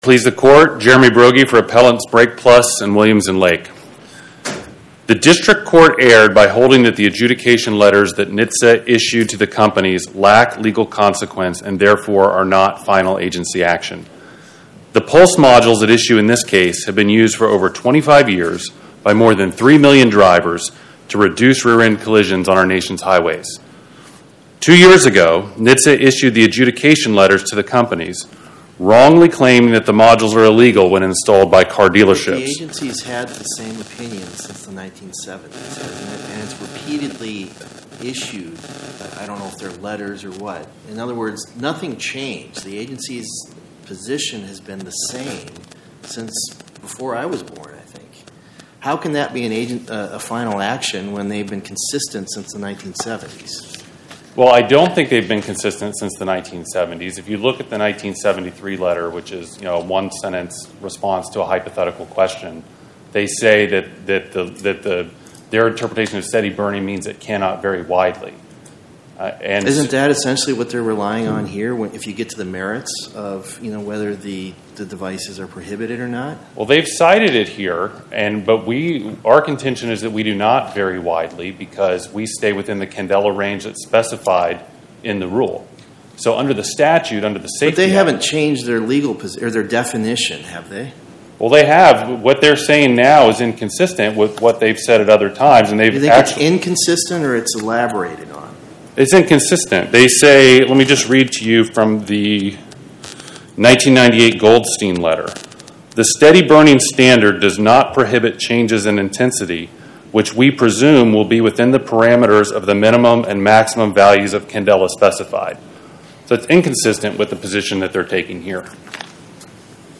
My Sentiment & Notes 24-2951: Brake Plus NWA, Inc. vs TRAN Podcast: Oral Arguments from the Eighth Circuit U.S. Court of Appeals Published On: Wed Sep 17 2025 Description: Oral argument argued before the Eighth Circuit U.S. Court of Appeals on or about 09/17/2025